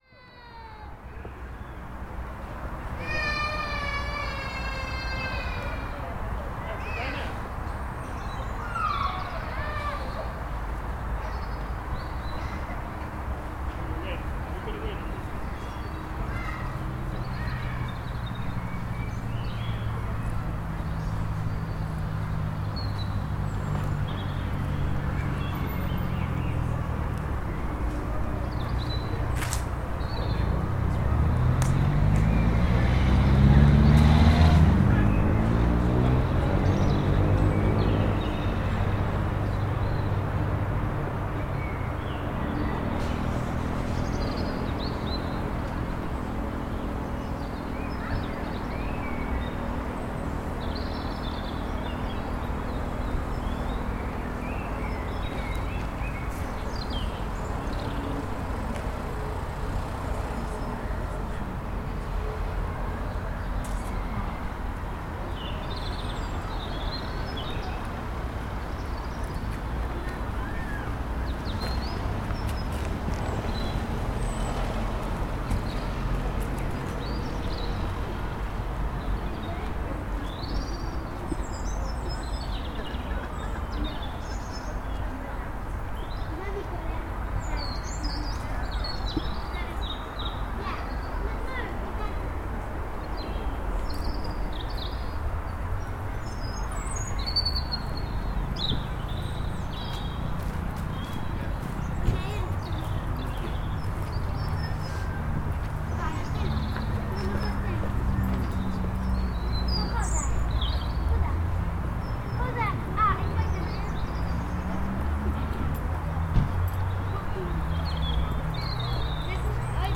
Birds, people and traffic in Royal Victoria Park, Bath.